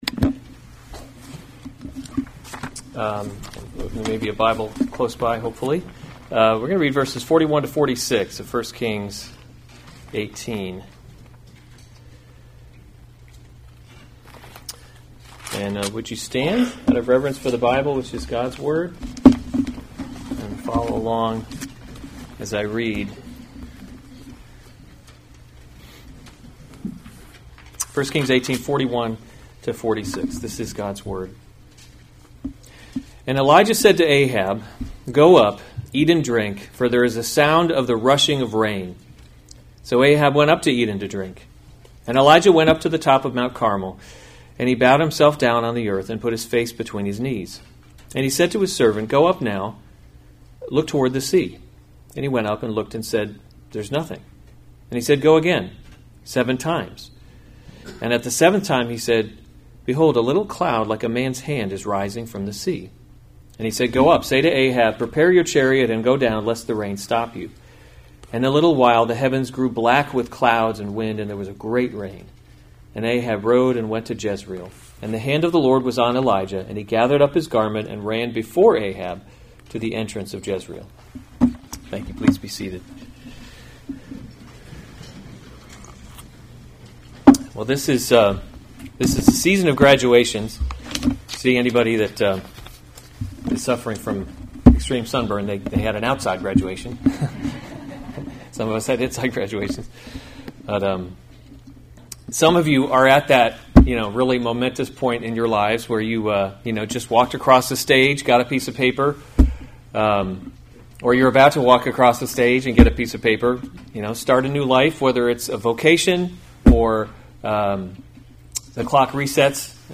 June 8, 2019 1 Kings – Leadership in a Broken World series Weekly Sunday Service Save/Download this sermon 1 Kings 18:41-46 Other sermons from 1 Kings The Lord Sends Rain […]